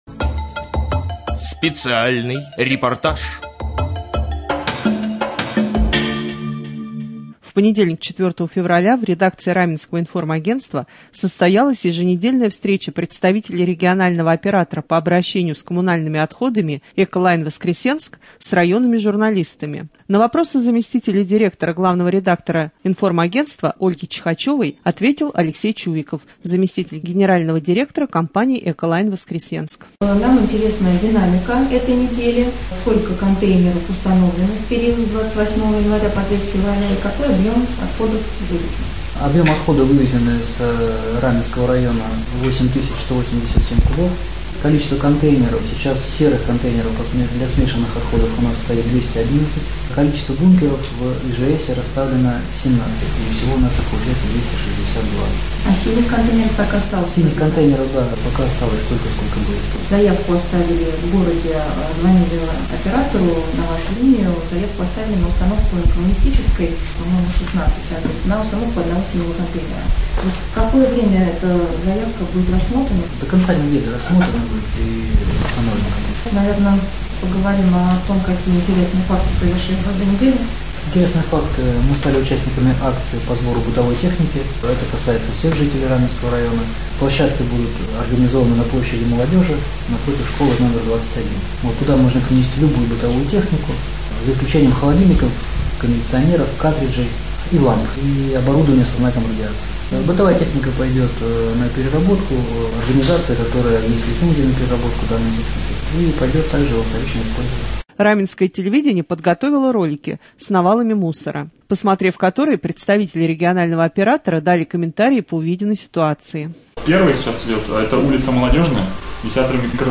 4.Рубрика «Специальный репортаж». В понедельник 4 февраля в редакции Раменского информагентства состоялась еженедельная встреча представителей регионального оператора по обращению с коммунальными отходами ООО «ЭкоЛайн — Воскресенск» с районными журналистами.